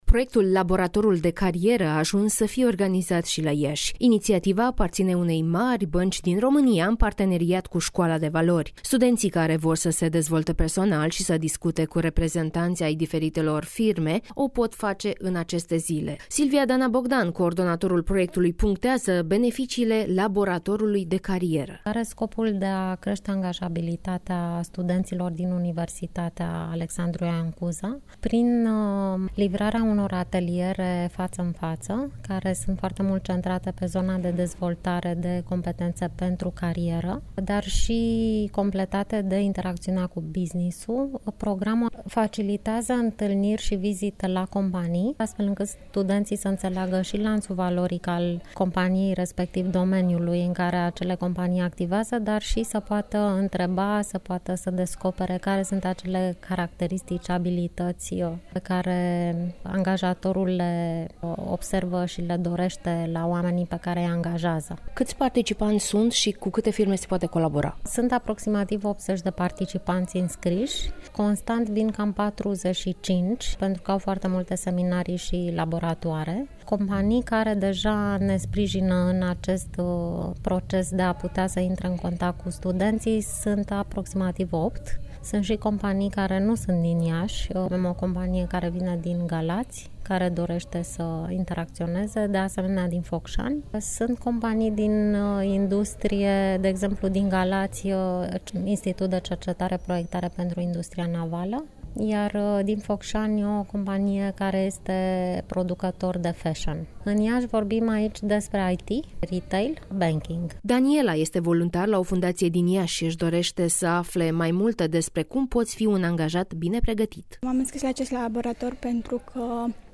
(EXCLUSIVITATE/REPORTAJ) Laboratorul de Cariere sau cheia pentru viitor mai bun